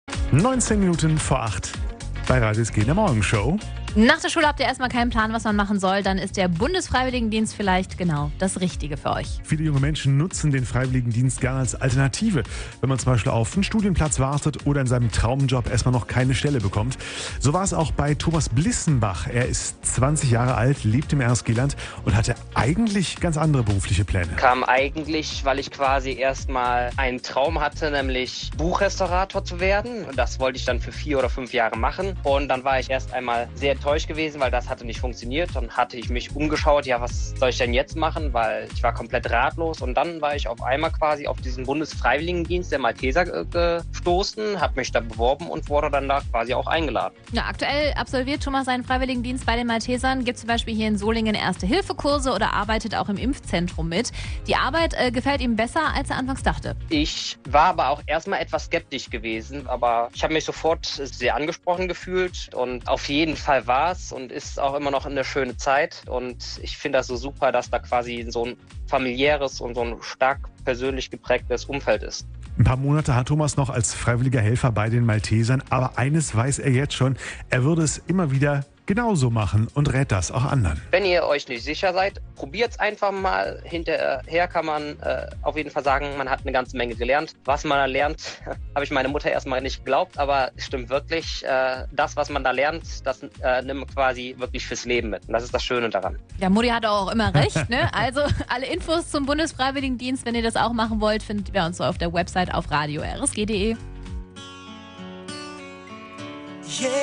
Wir haben junge Menschen aus dem RSG-Land nach ihren Erfahrungen in ihrer Zeit als "Bufdi" gefragt. Hier findet ihr die Interviews zum Nachhören.